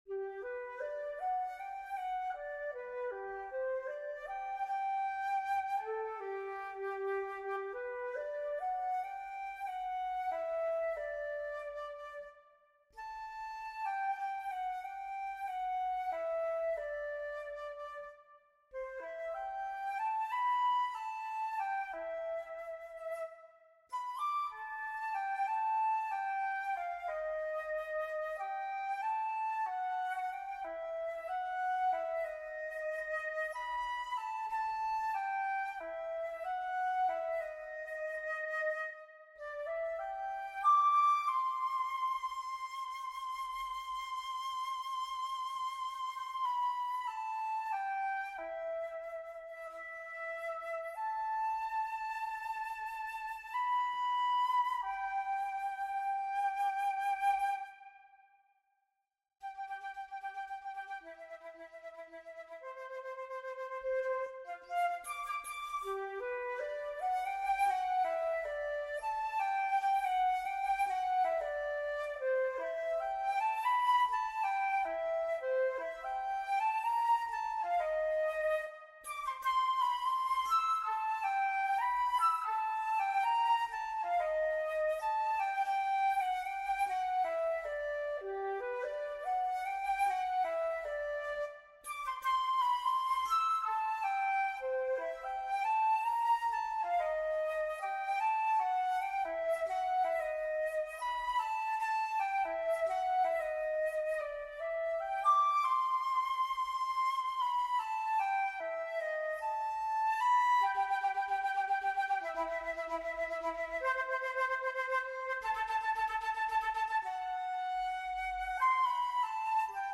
This arrangement is for solo flute.